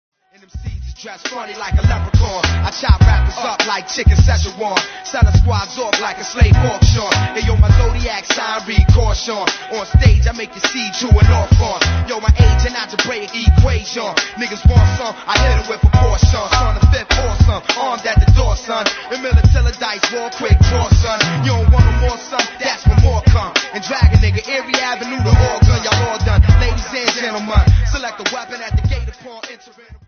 マイクリレーも最高です!!
# 90’S HIPHOP